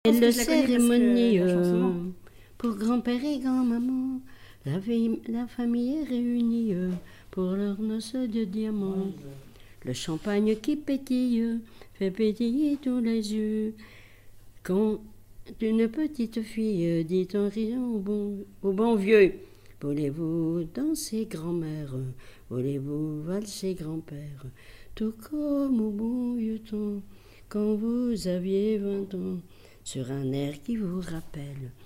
témoignage et chansons
Pièce musicale inédite